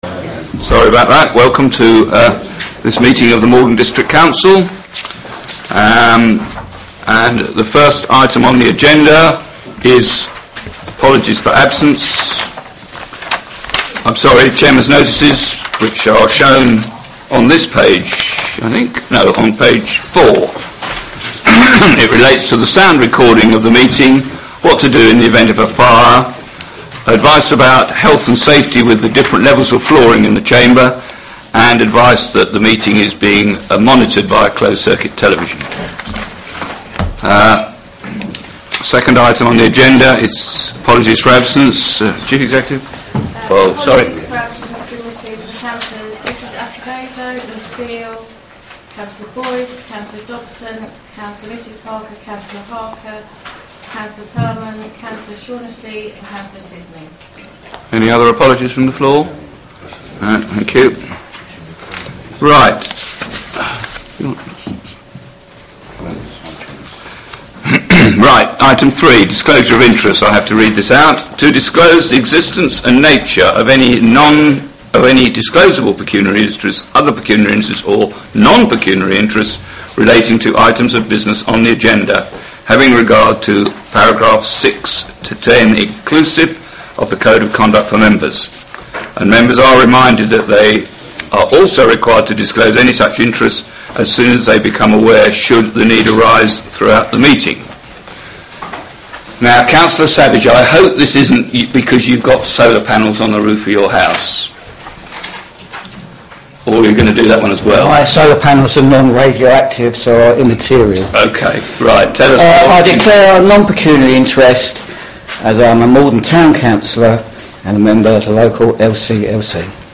Council (Extraordinary - Planning) meeting audio recordings | Maldon District Council